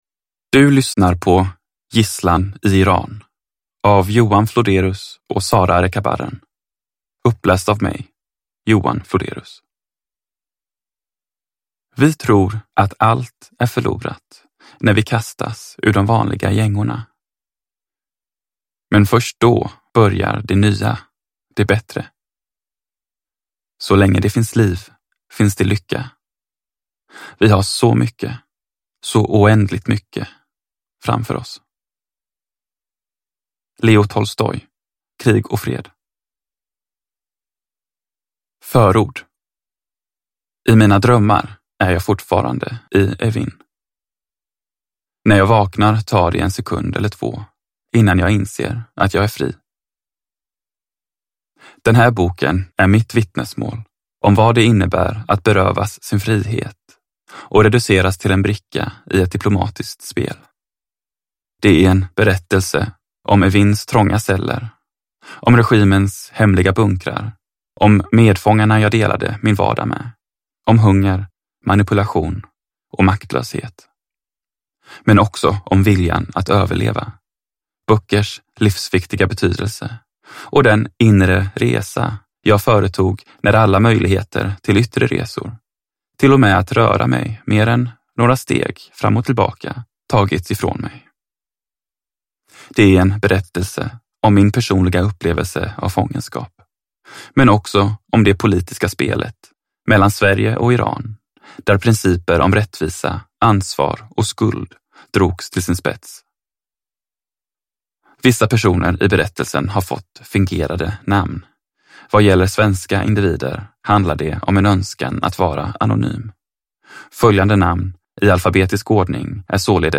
Gisslan i Iran : Mina 790 dagar i fångenskap – Ljudbok
Uppläsare: Johan Floderus